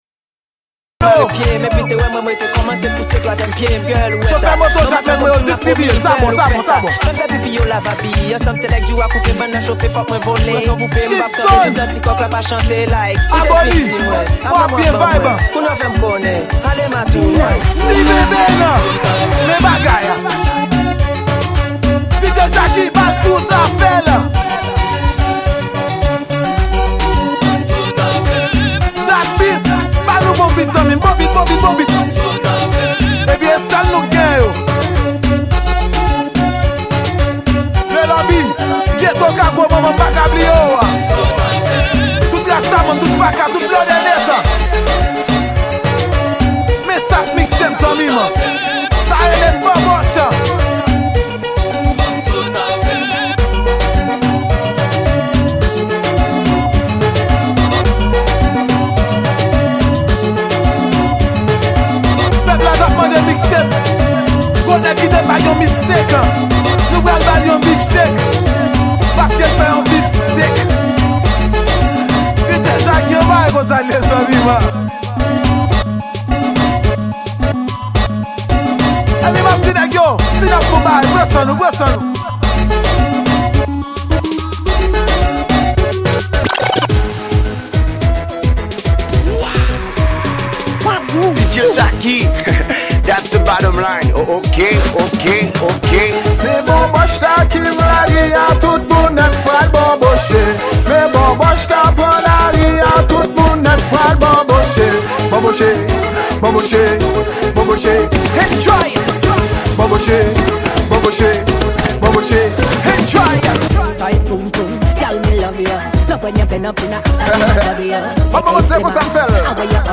Genre: Mix